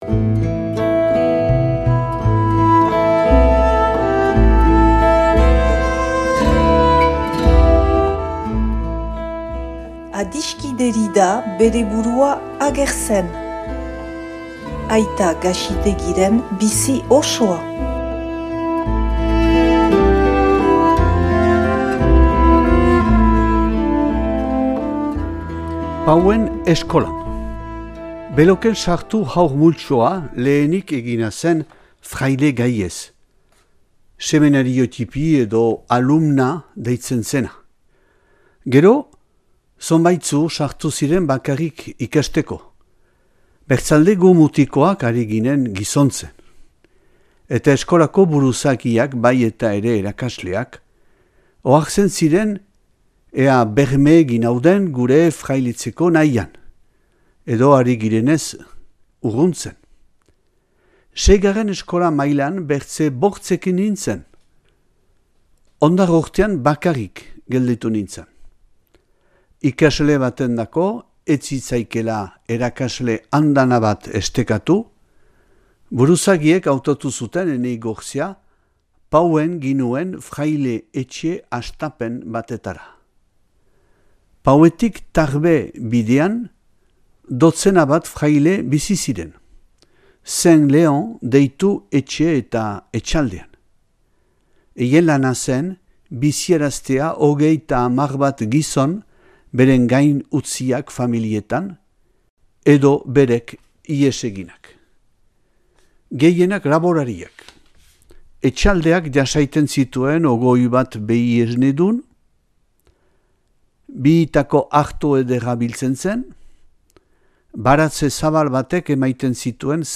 egin irakurketa bat dauzuegu eskaintzen.